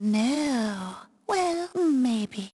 Madame Clairvoya voice clip